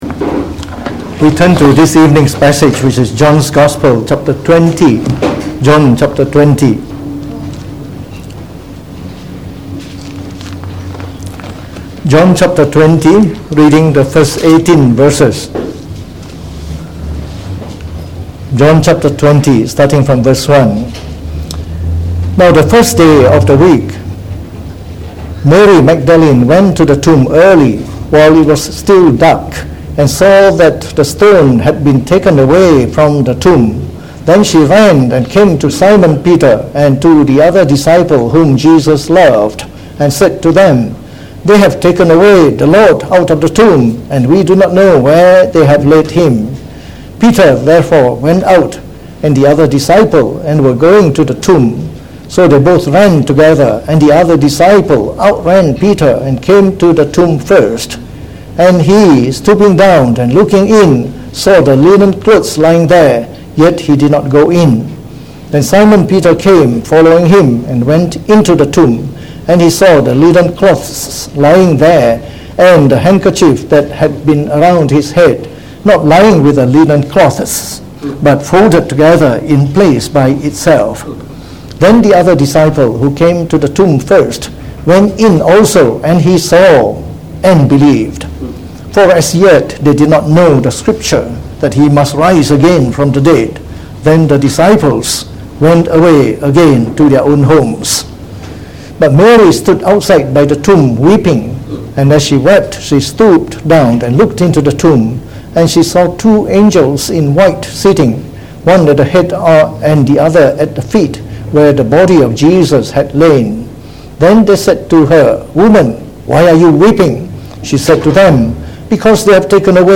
Preached on the 28th of July 2019.